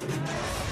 speeder_boost1.wav